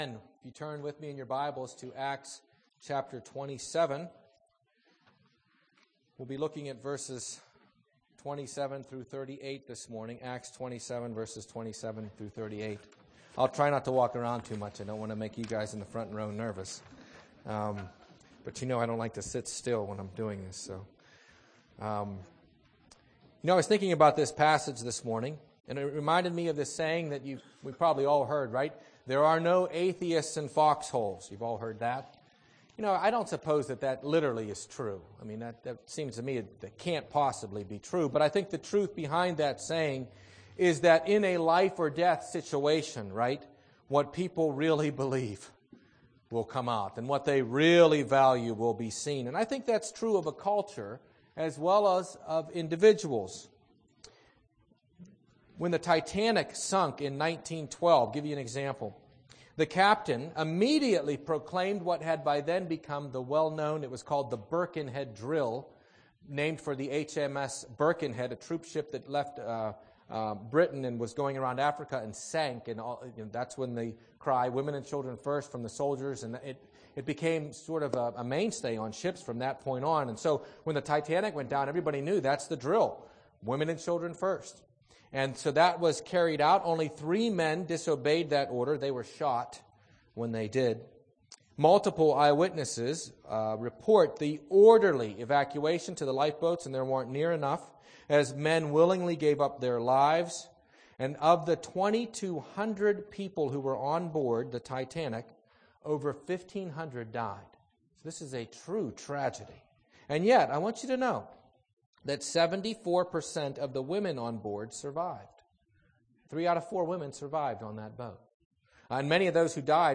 00:00 Download Copy link Sermon Text Acts 27:27–38